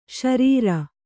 ♪ śarīra